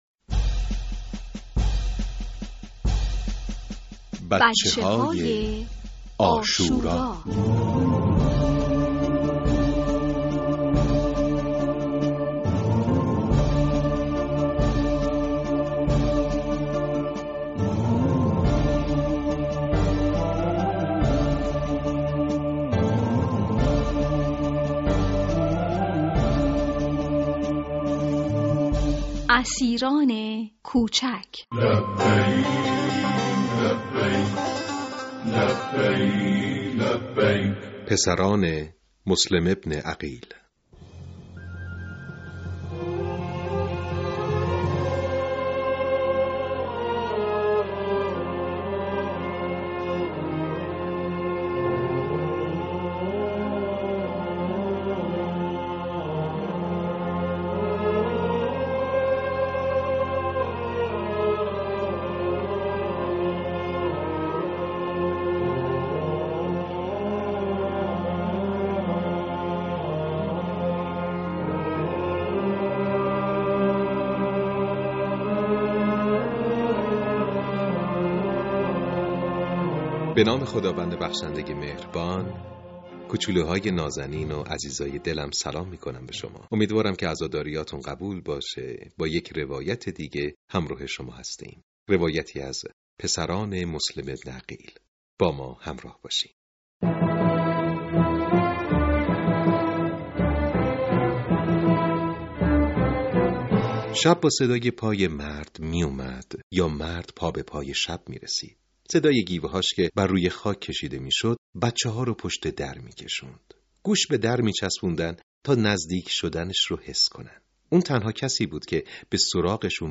# بچه های عاشورا # مسلم بن عقیل علیه السلام # قصه # ماه محرم الحرام # تربیت فرزند # پادکست # کتاب صوتی